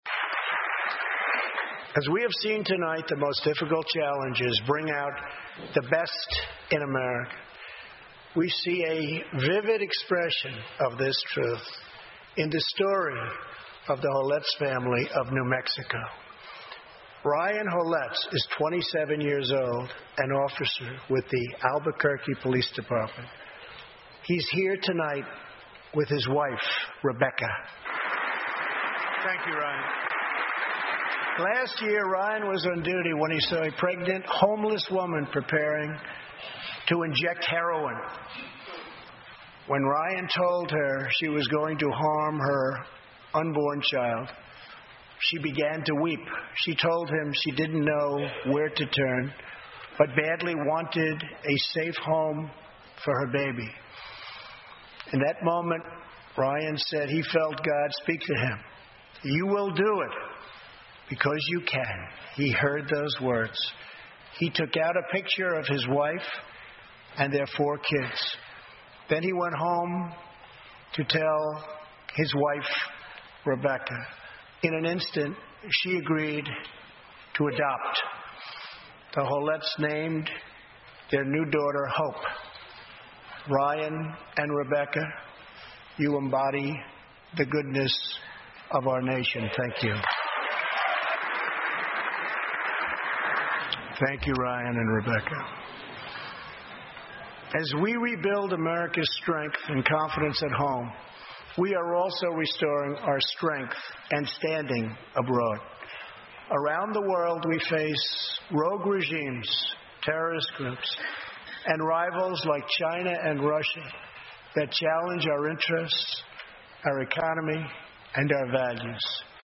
欧美名人演讲 第111期:美国总统川普首次国情咨文演讲(18) 听力文件下载—在线英语听力室